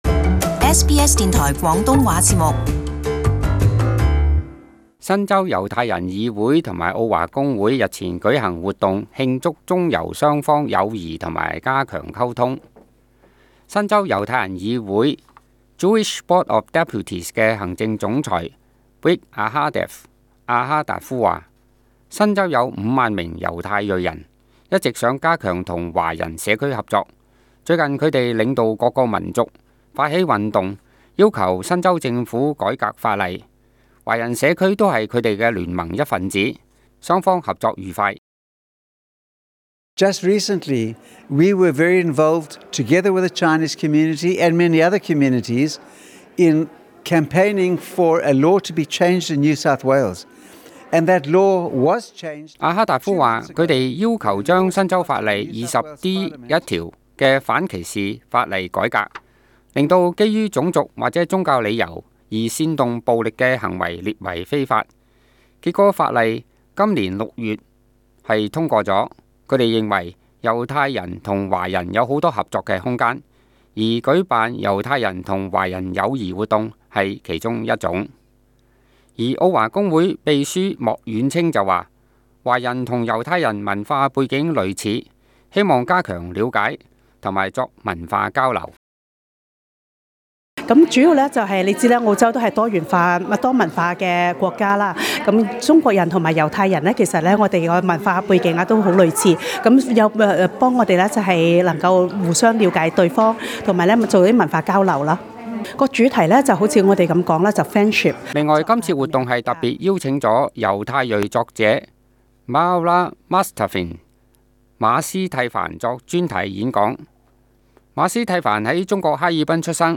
【社區專訪】澳華公會與猶太人議會辦友誼活動